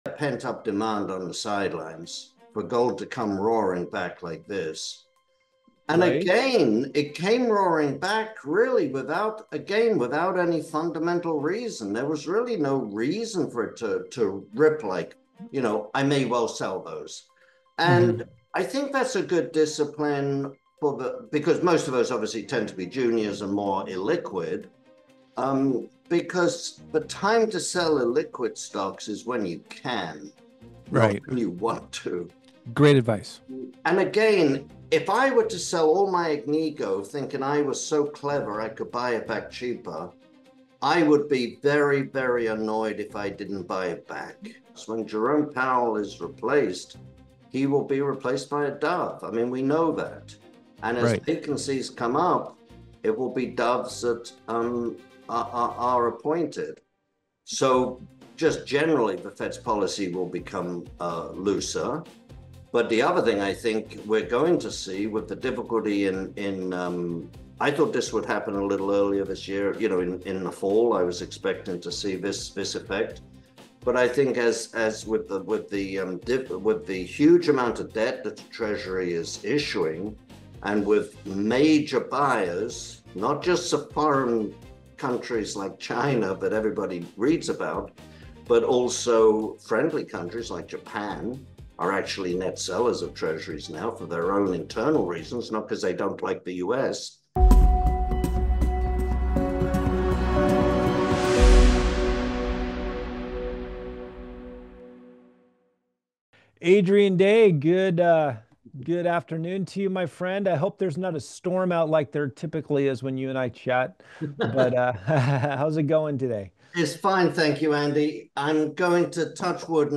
Gold ripped higher again and the pullback that was supposed to shake out weak hands barely registered. In this Natural Resource Stocks interview